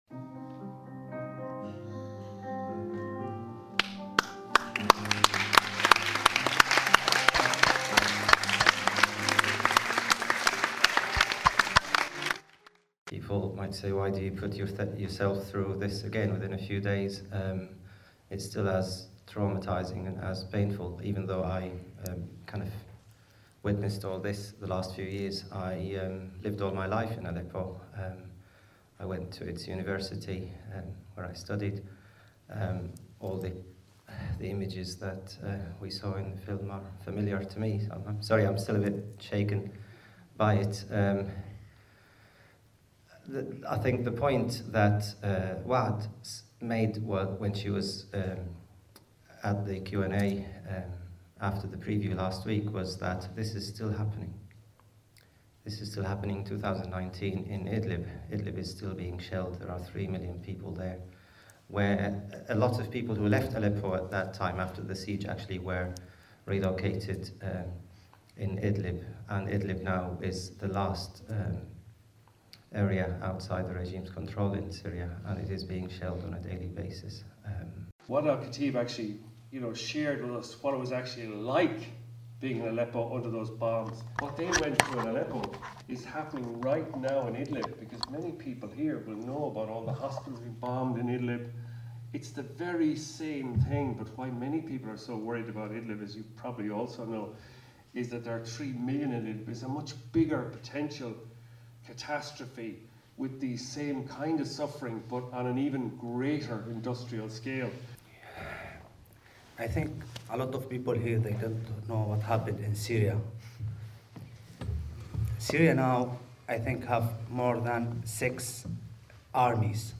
Audience hold up posters STOP BOMBING HOSPITALS following discussion after screening of Waad al-Kateab’s compelling FOR SAMA in the IFI’s Cinema 1 (Sept.14, 2019).